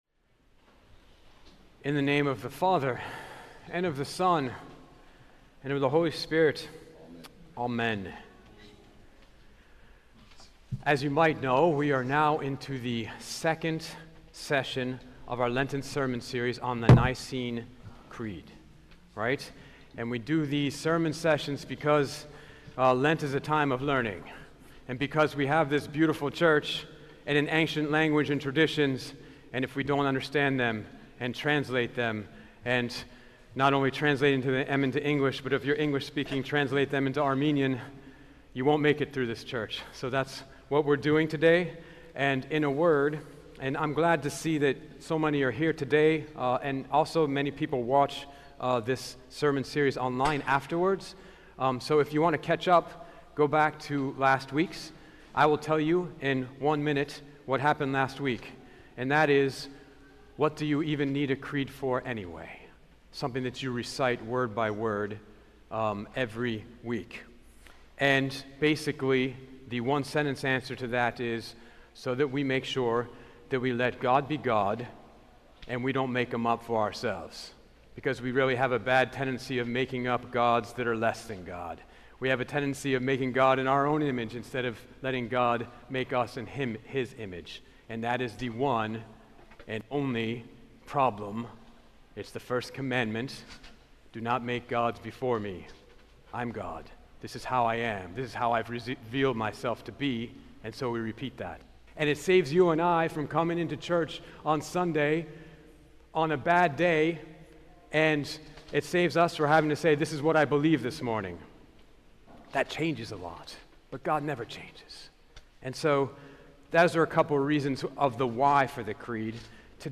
In the second session of our Lenten Sermon series on the Nicene Creed, we talk about God the Father, a God so great that we must choose our words very, very carefully!